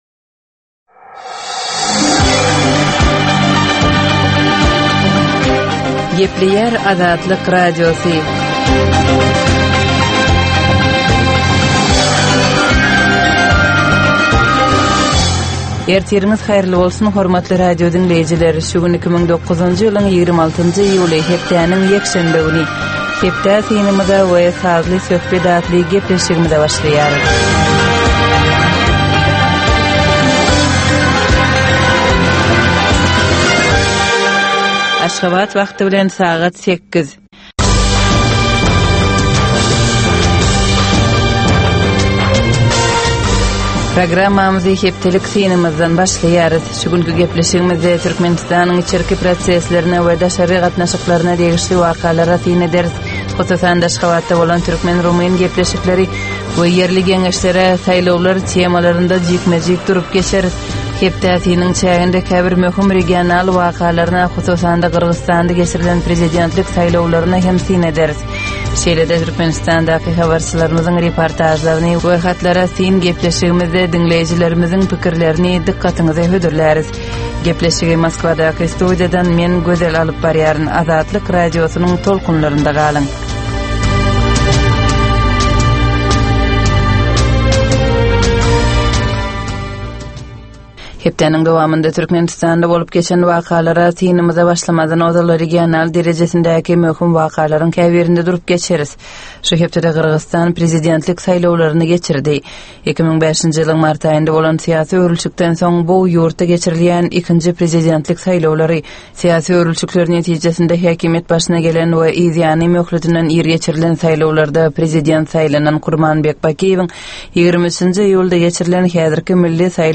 Tutus geçen bir hepdänin dowamynda Türkmenistanda we halkara arenasynda bolup geçen möhüm wakalara syn. 30 minutlyk bu ýörite programmanyn dowamynda hepdänin möhüm wakalary barada gysga synlar, analizler, makalalar, reportažlar, söhbetdeslikler we kommentariýalar berilýar.